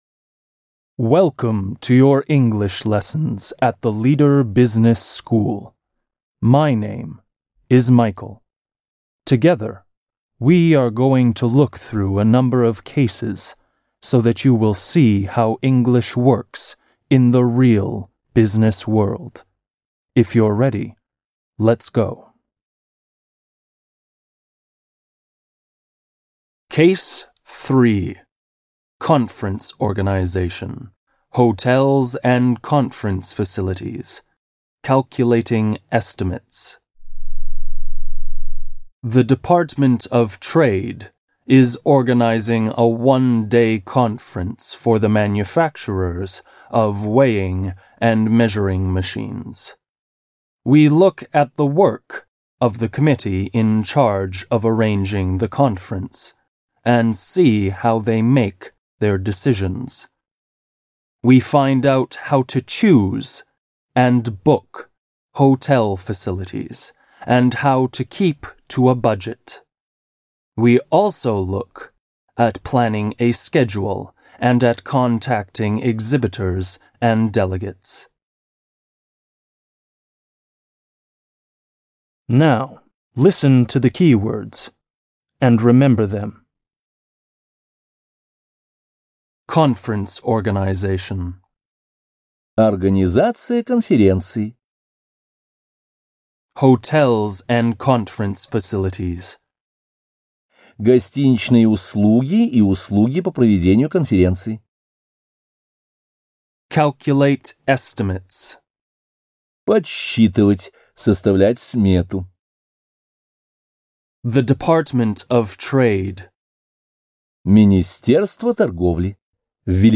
Аудиокнига Let's Speak English. Case 3. Conference Organization | Библиотека аудиокниг